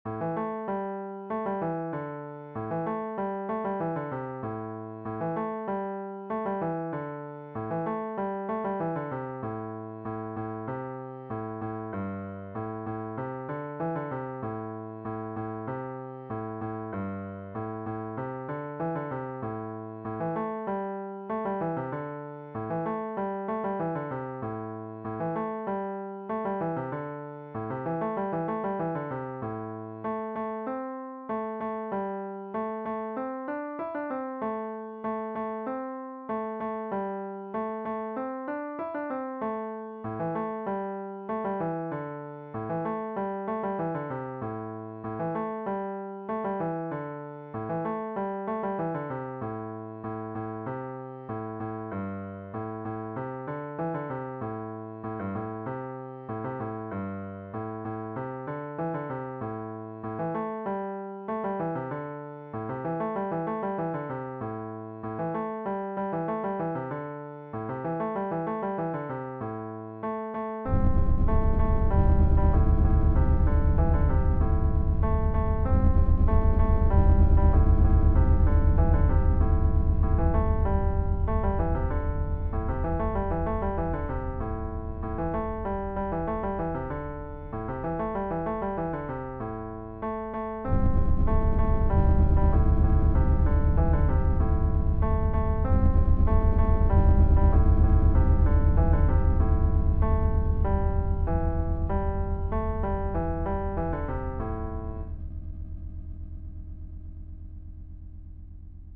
DIGITAL SHEET MUSIC - CELLO SOLO
Cello Melody Only, Traditional Fiddle Tune